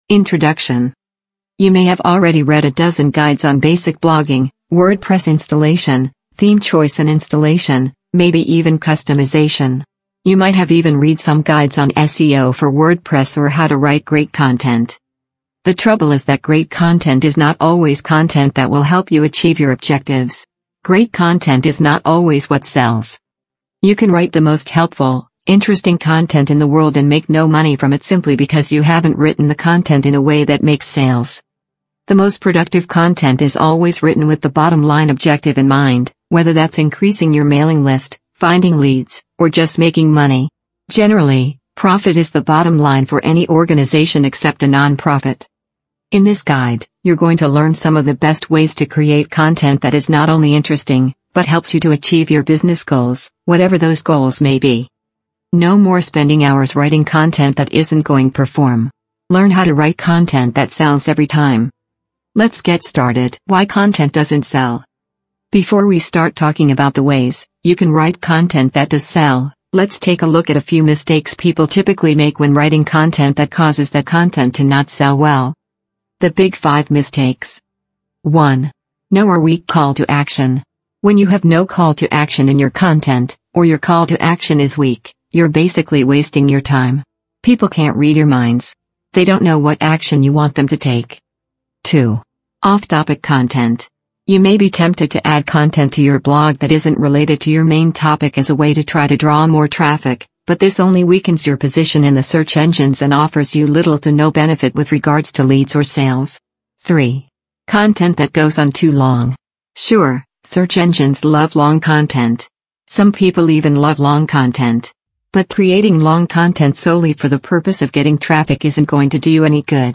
Hosted by digital insiders, each episode delivers sharp commentary, real-world case studies, and expert interviews that reveal what still works in blogging, what’s outdated, and what’s next